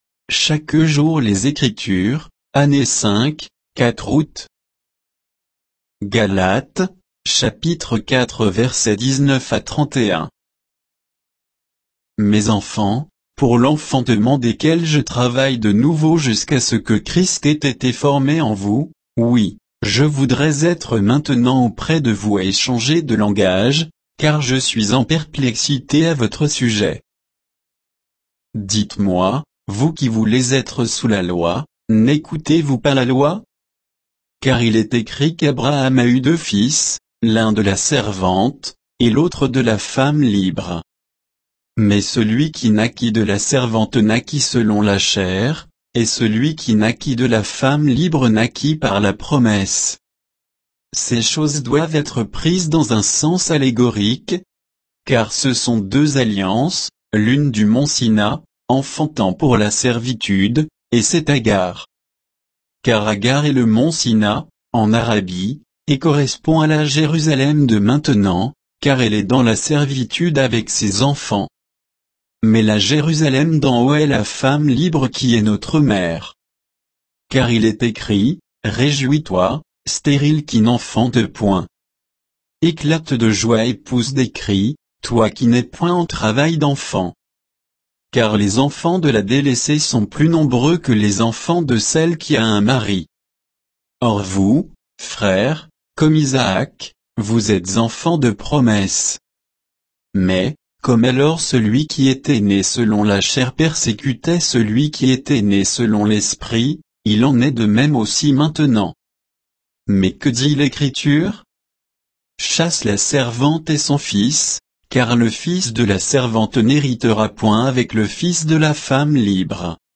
Méditation quoditienne de Chaque jour les Écritures sur Galates 4